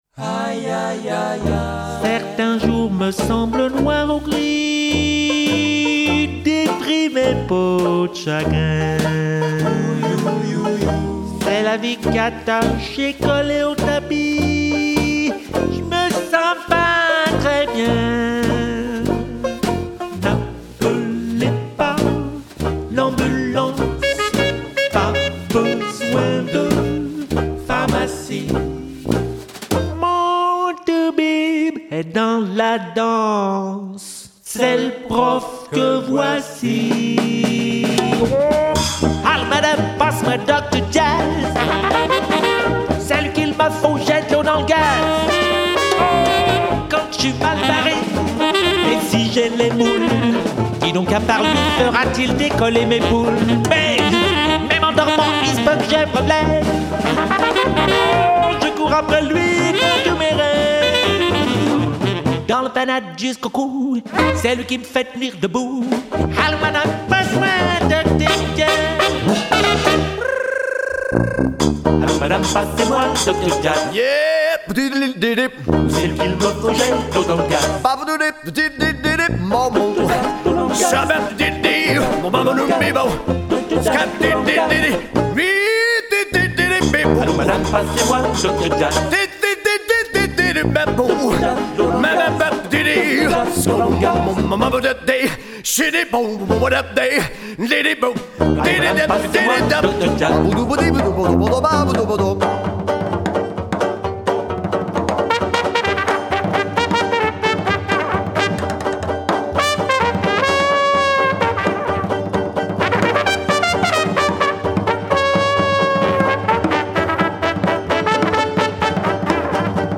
Le mini big band
le QUARTET :
(sax alto, flûte à bec, trompinette, vocal ...)
(banjo, guitare,  cornet à pistons, vocal ...)
(sousaphone, percussions, vocal ...)
(percussions, claviers, vocal...)